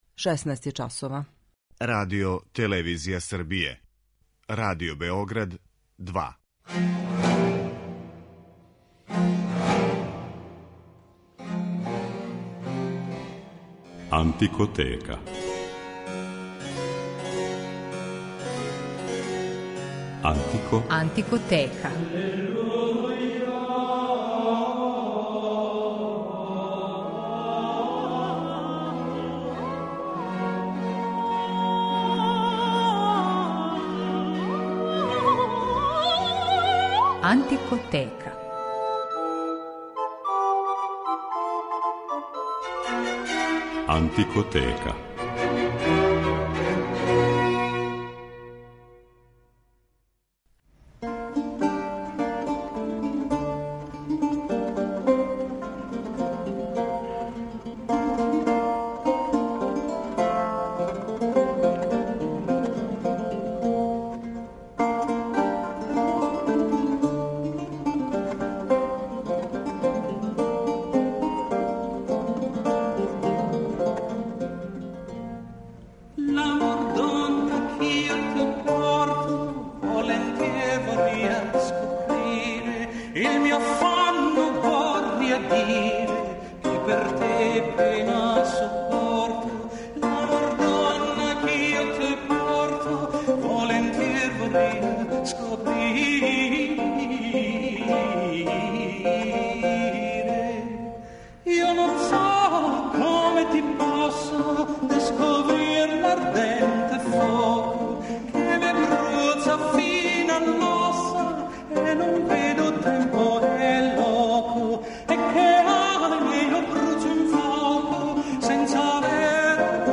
Овај световни музички облик најчешће љубавног садржаја, карактеристичан је за крај 15. и поцетак 16. века у Италији и то за њене северне крајеве - за Милано, Верону, Ферару, Венецију и нарочито Мантову, где су на двору Гонзага радили највећи мајстори ове значајне вокално-инструменталне форме.